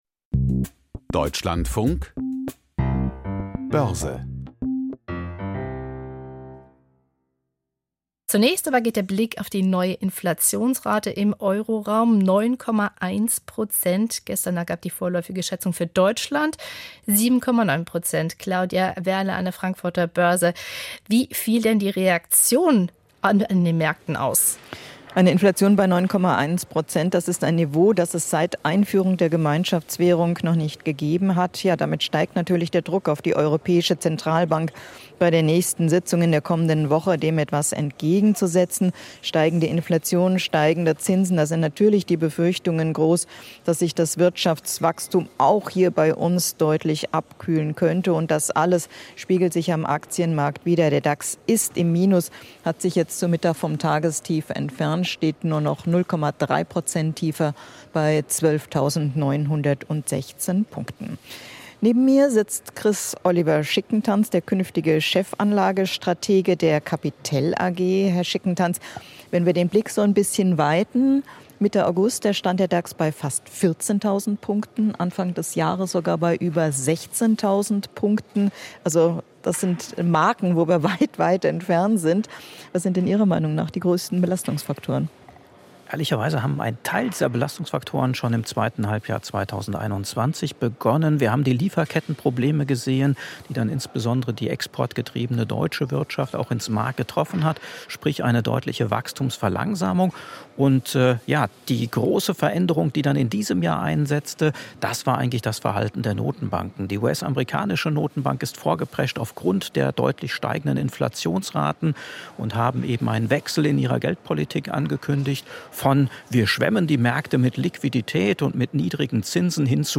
Börsengespräch aus Frankfurt